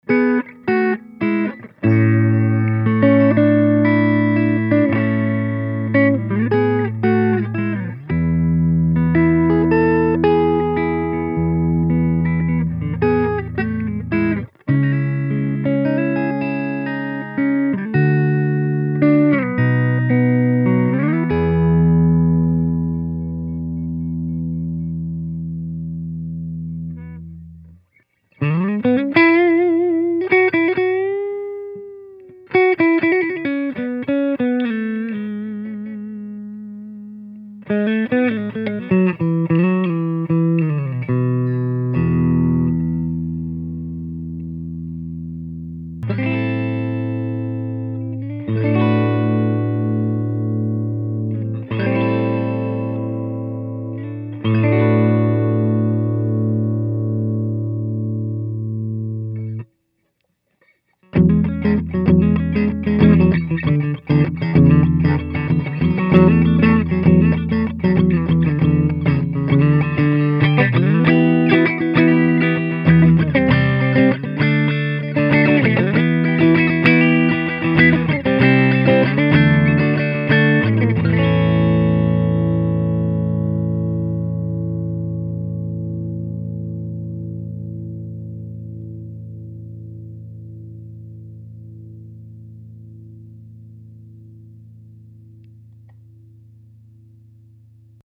Played clean, the Medusa 150 has a very acoustic-like response. This has a lot to do with the big bottom end that helps to give the clean tone a much bigger sound.
Despite that, I still got a real acoustic response that was VERY pleasing.